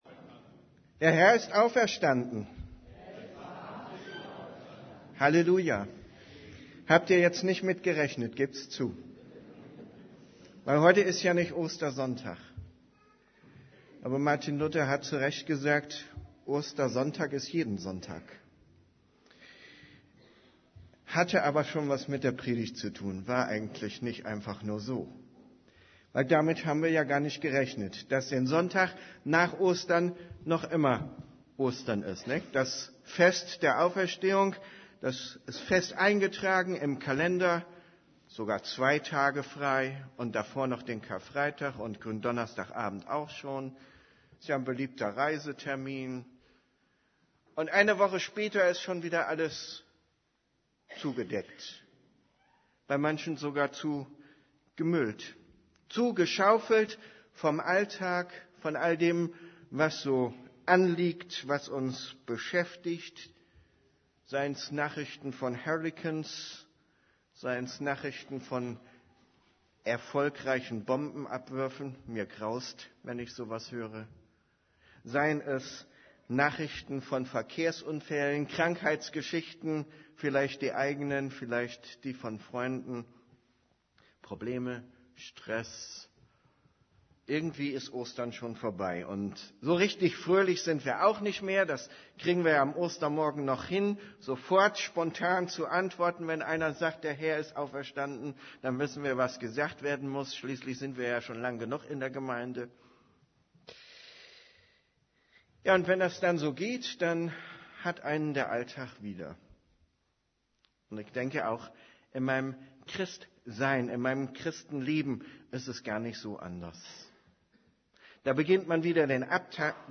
> Übersicht Predigten Als es Tag wurde stand Jesus am Ufer Predigt vom 01. Mai 2011 Predigt Predigttext: Johannes 21, 1-14 1 Danach offenbarte sich Jesus abermals den Jüngern am See Tiberias.